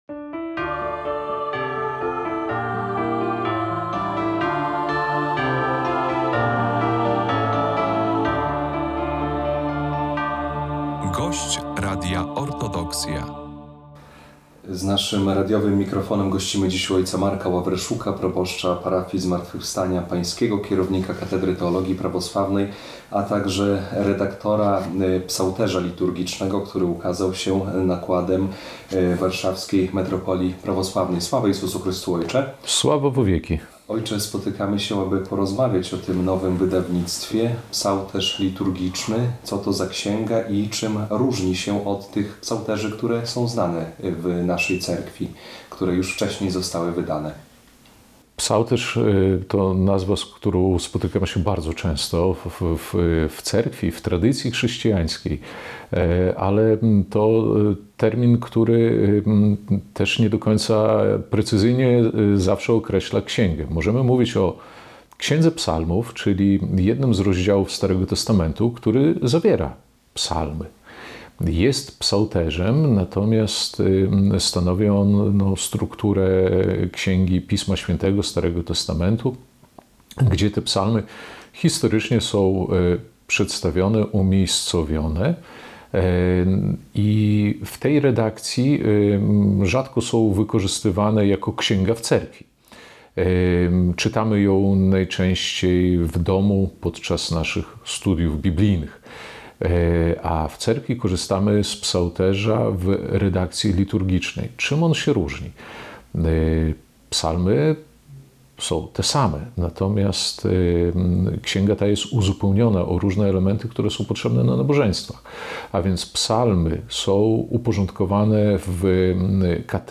To jedna z najważniejszych i najbardziej podstawowych ksiąg liturgicznych Prawosławia. O nowym przekładzie Psałterza liturgicznego rozmawialiśmy